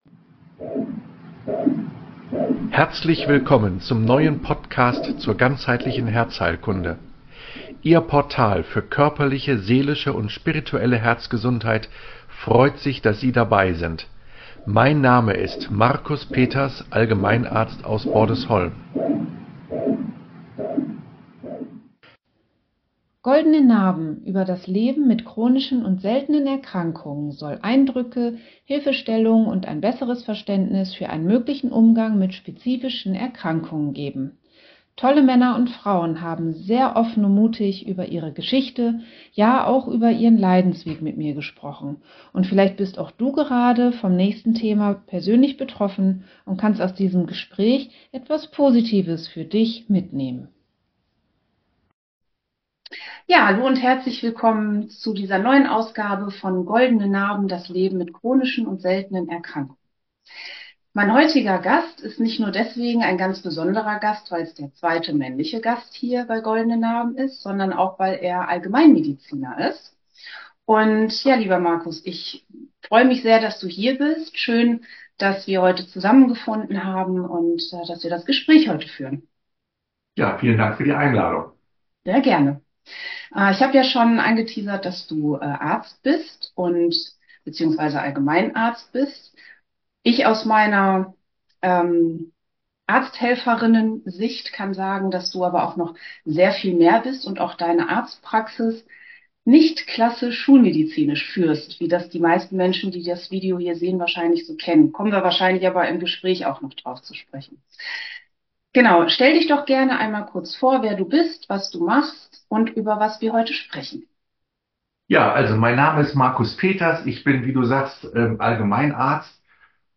Meine Geschichte mit Herzklappenfehler ‍🩹: Ein Interview über Hoffnung und Heilung Heute lade ich dich herzlich ein, mehr über meine persönliche Reise mit Herzklappenfehler zu erfahren.